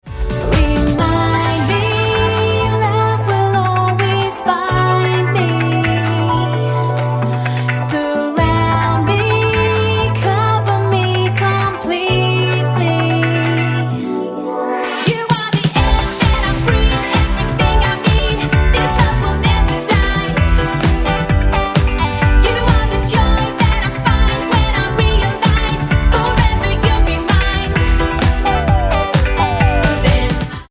Em